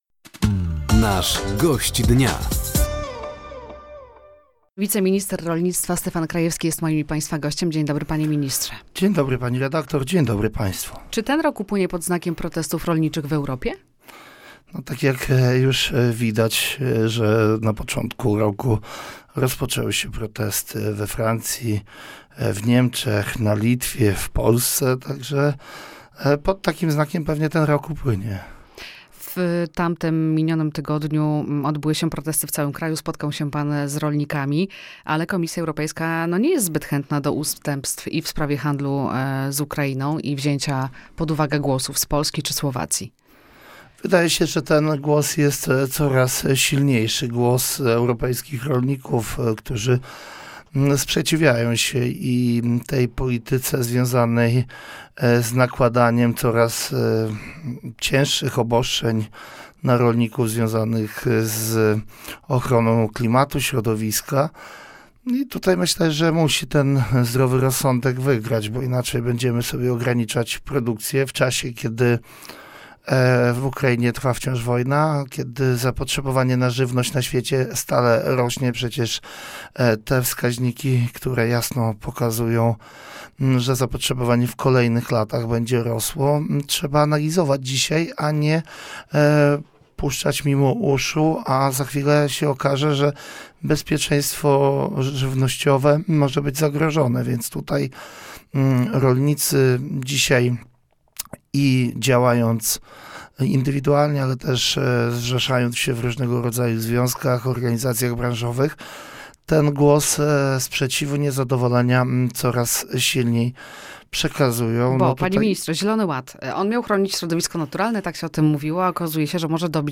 Gościem Dnia Radia Nadzieja był Stefan Krajewski, wiceminister rolnictwa i rozwoju wsi. Tematem rozmowy były aktualne problemy rolników oraz trwające protesty.